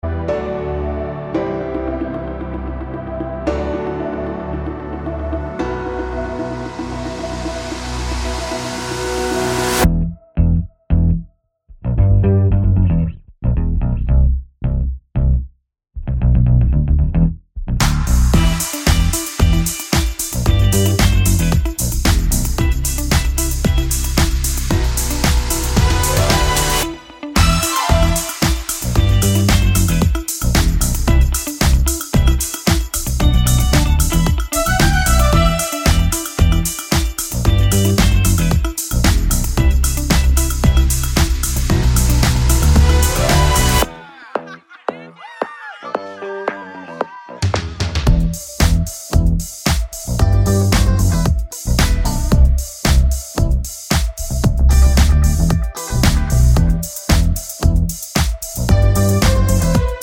no Backing Vocals Pop (2020s) 3:43 Buy £1.50